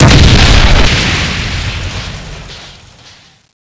destruction.wav